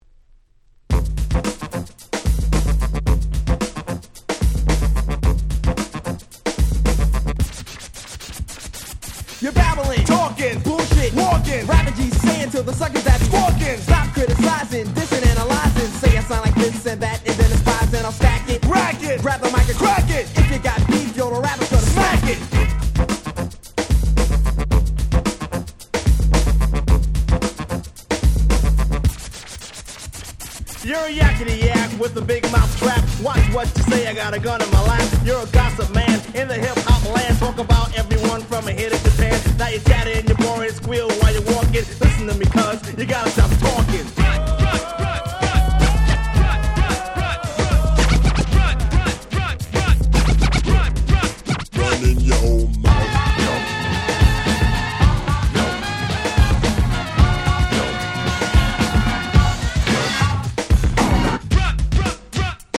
88' Old School / Middle School Classics !!
80'sのHip Hopは最近どんどん入手し辛くなっております。